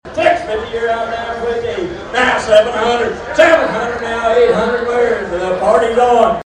Bidding was hot and heavy at the St. Patrick’s Committee annual auction at Bruffs Sports Bar and Grill on Friday night.
0686-auction.mp3